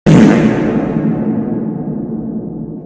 Texture-Pack/assets/minecraft/sounds/block/chest/close.ogg at 12810dbe7e5eebd86045b1a3aab25ff930b4e031